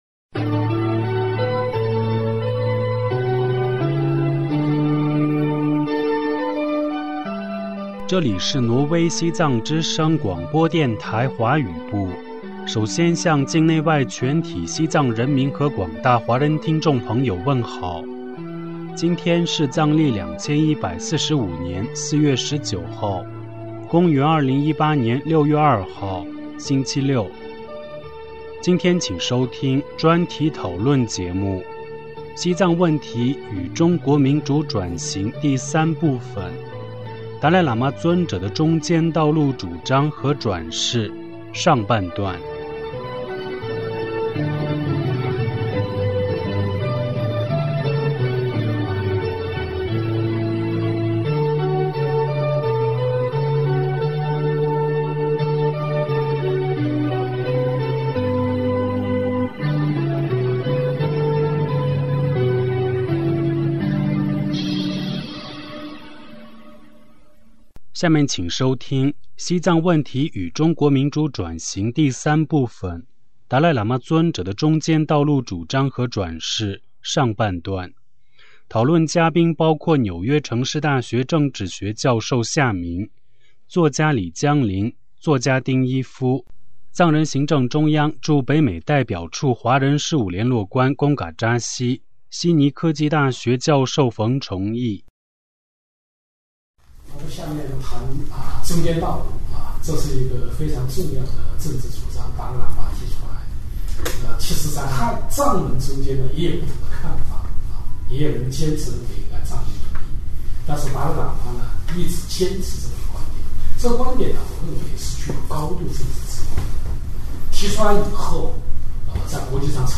专题讨论节目：《西藏问题与中国民主转型》第三部分“达赖喇嘛尊者的中间道路主张和转世”——上半段